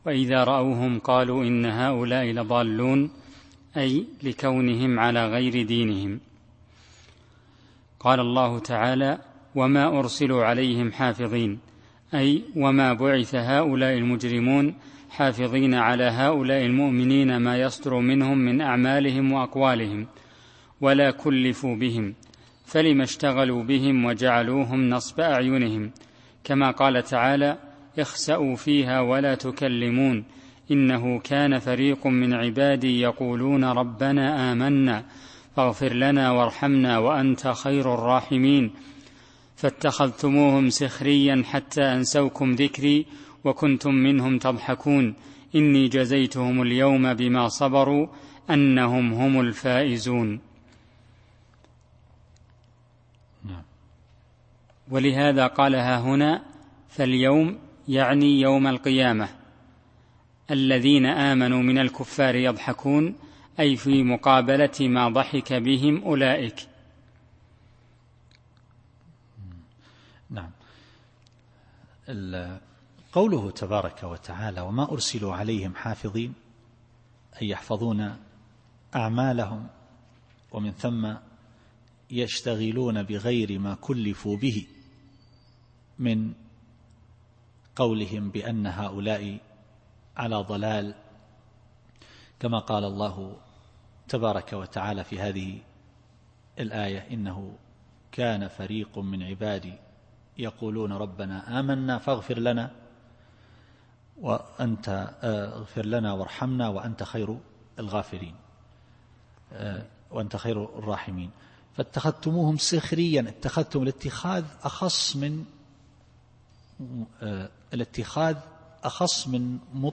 التفسير الصوتي [المطففين / 32]